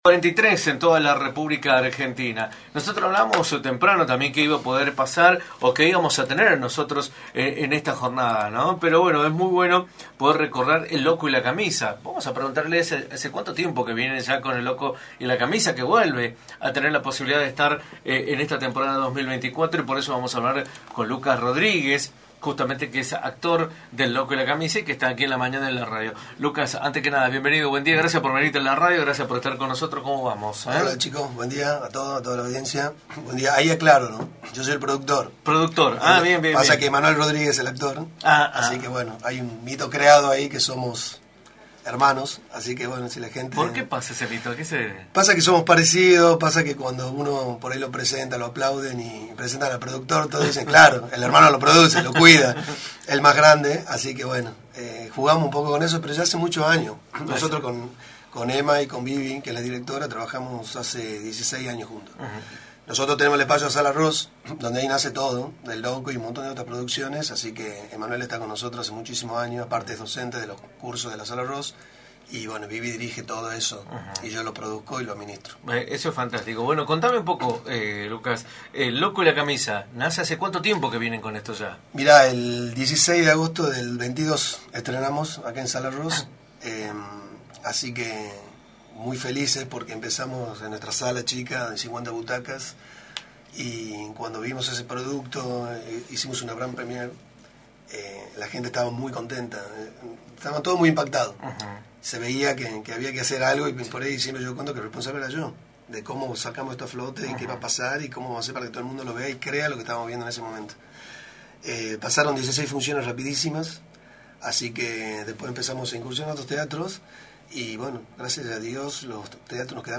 entrevista para “La Mañana del Plata”, por la 93.9.